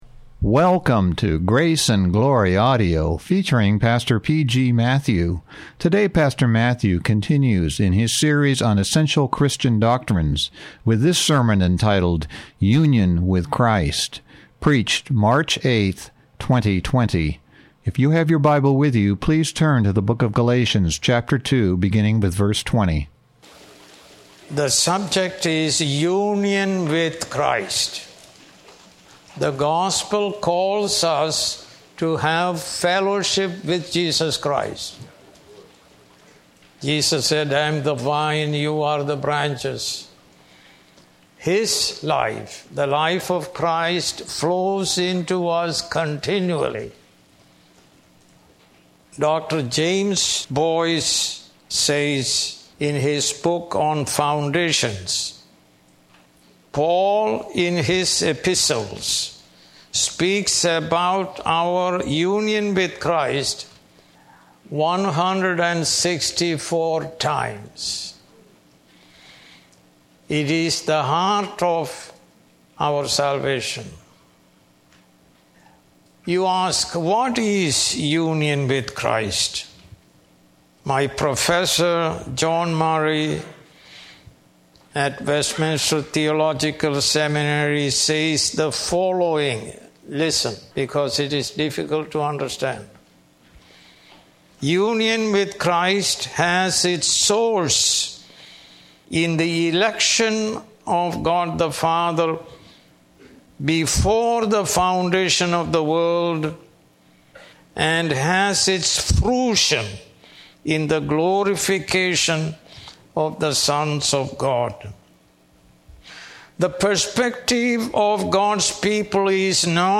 More Sermons